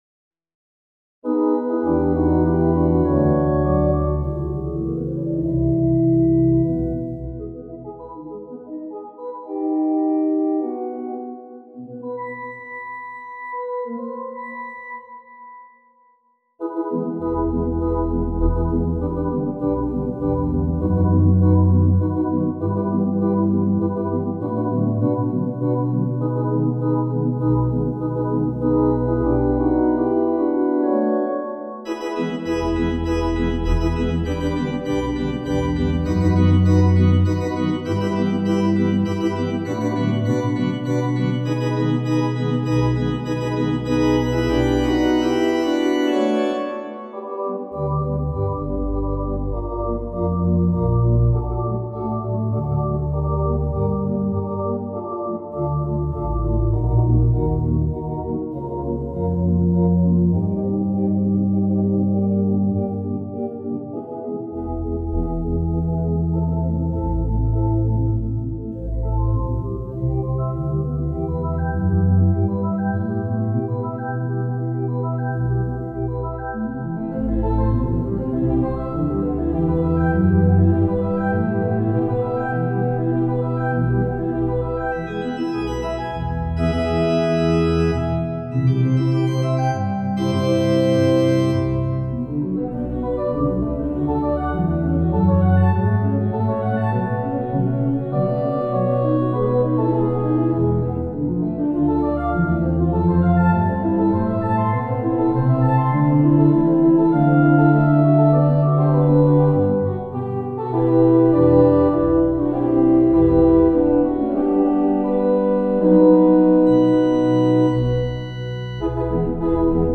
for organ Triads sharing a common tone, the fifth, move through a rapid, rhythmic alternation of octaves, and these through a number of related tonal domains.